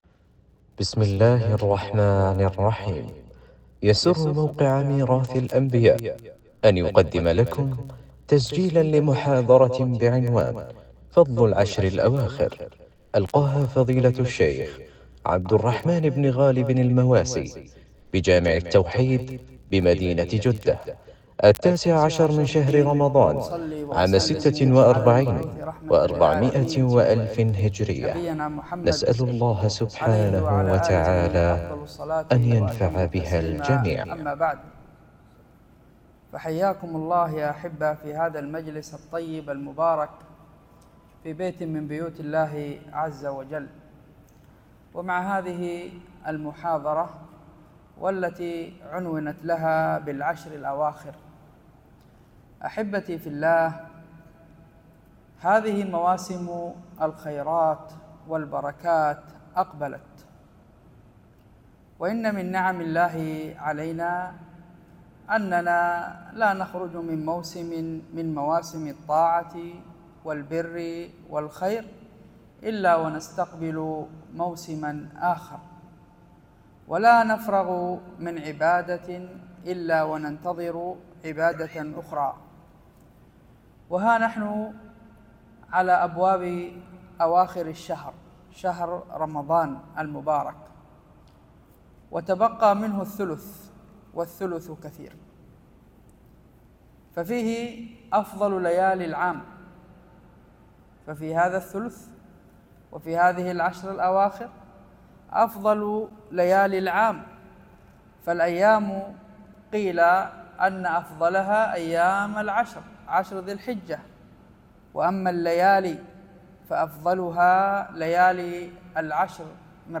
محاضرة
محاضرة-فضل-العشر-الأواخر-.mp3